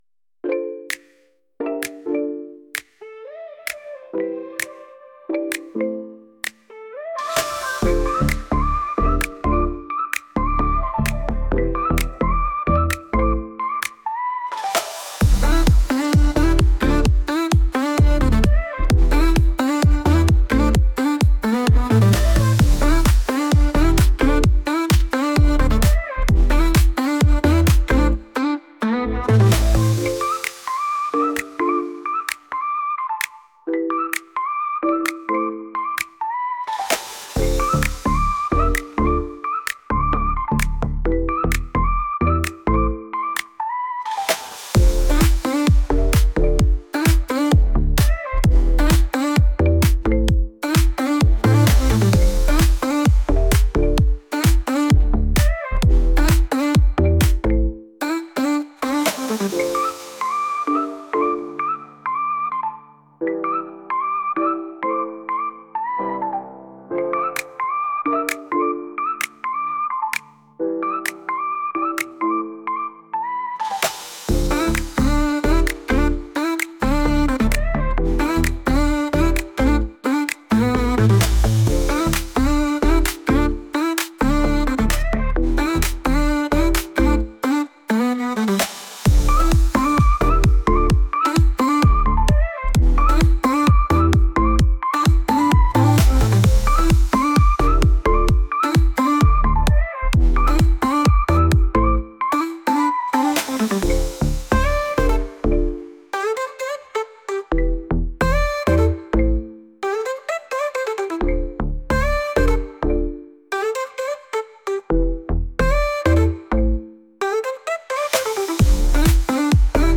韓国風のエレクトリック曲です。